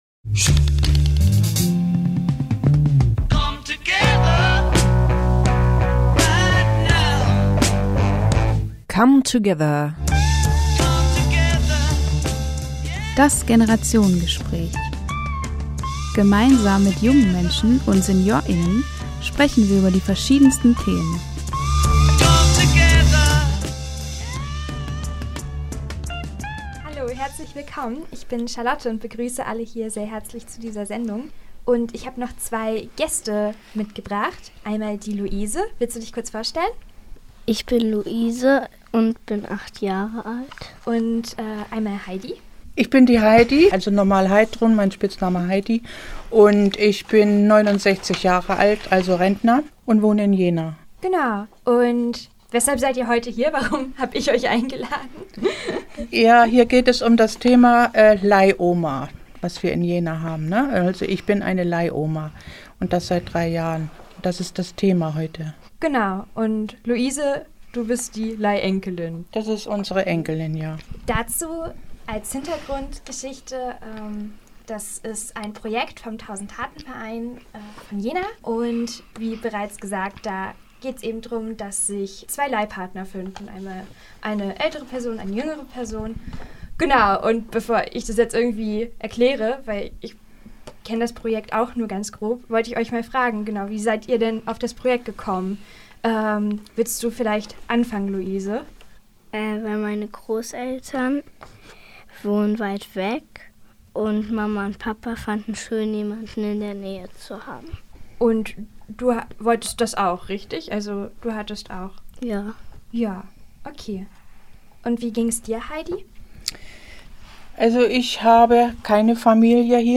Wir haben mit beiden Bands gesprochen, was diesen Abend und was Jena für sie besonders macht.